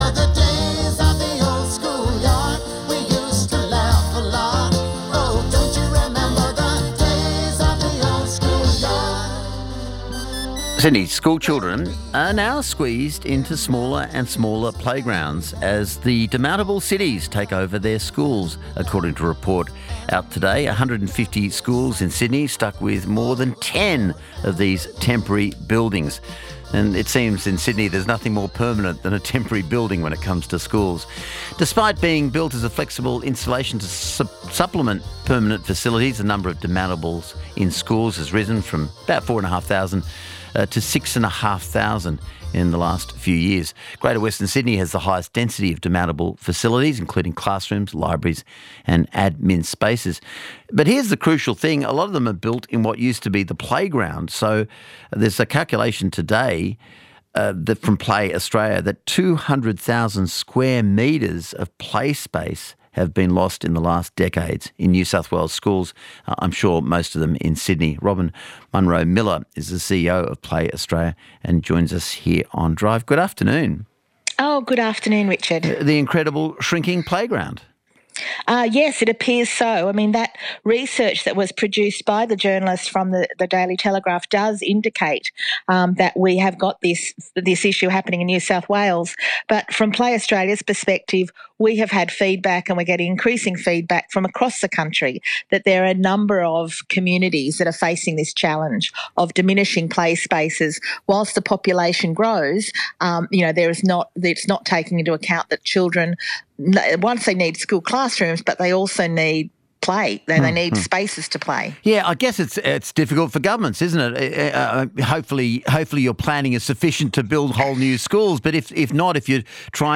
aav ABCRadioSyd Drive.mp3